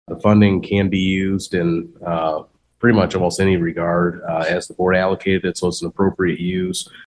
And County Administrator Kevin Catlin says the funding was not committed to vets.